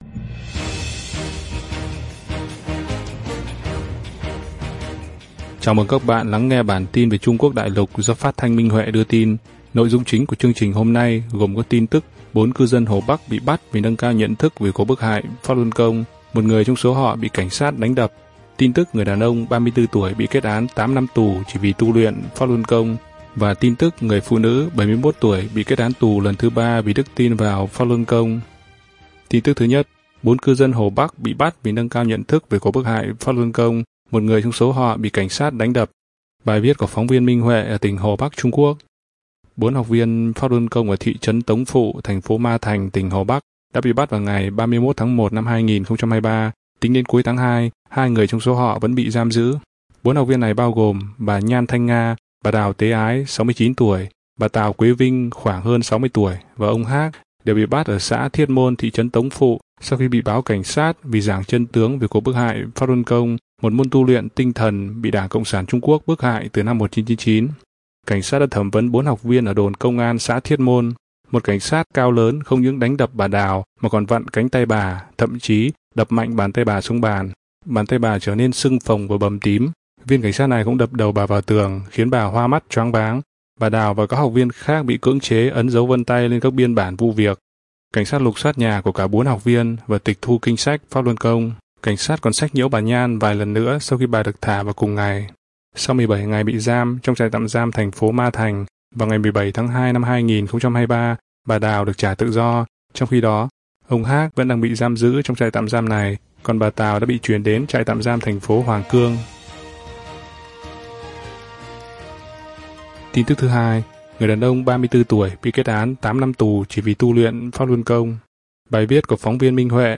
Chương trình phát thanh số 3: Tin tức Pháp Luân Đại Pháp tại Đại Lục – Ngày 01/03/2023